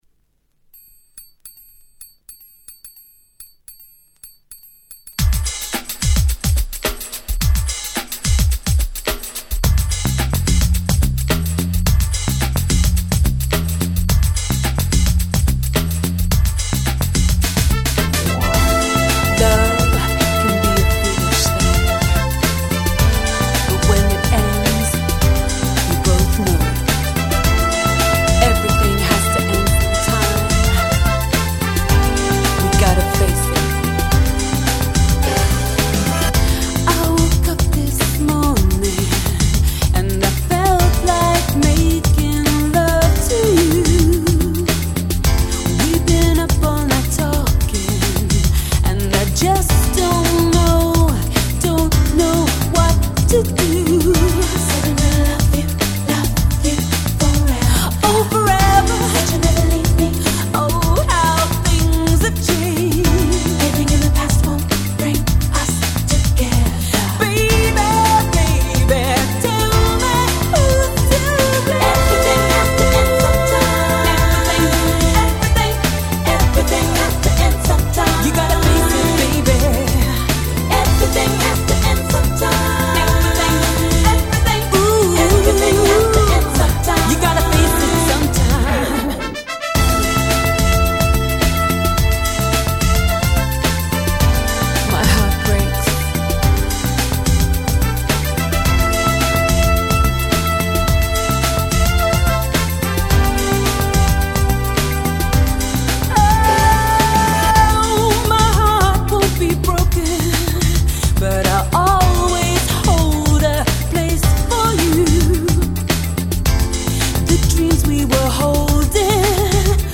91' Nice UK Soul !!
爽やかに洗練されたメロディーが心地良いめちゃくちゃ良い曲！！